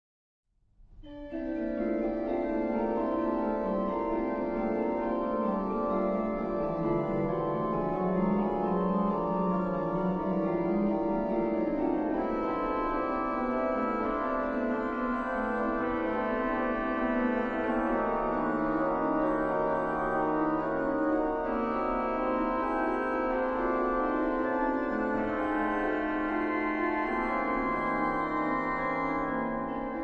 Organy
Organy firmy Eule w Archikatedrze sw, Jana w Warszawie